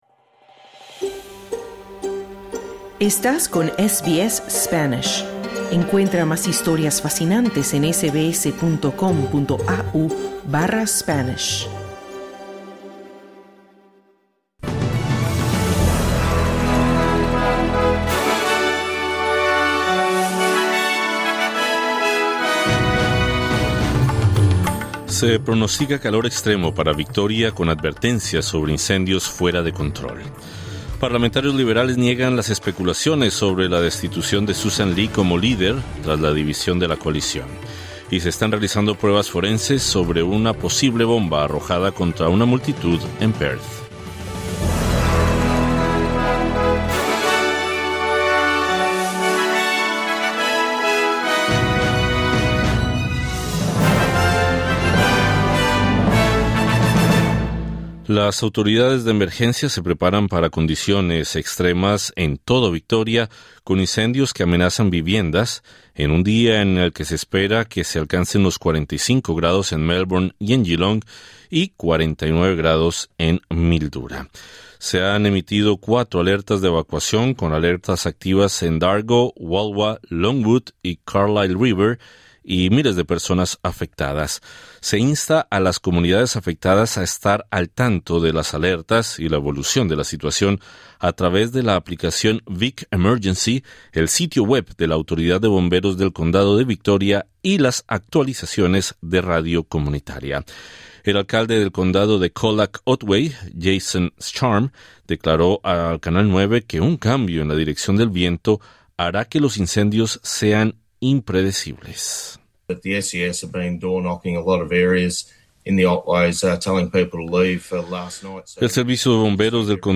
Las autoridades de emergencia se preparan para condiciones extremas en toda Victoria, en un día en el que se espera que se alcancen los 45 grados en Melbourne y 49 en Mildura. Escucha el resumen informativo de este martes 27 de enero 2026.